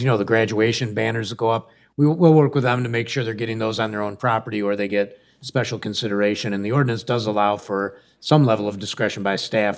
City Manager Pat McGinnis says one time violators will get a warning.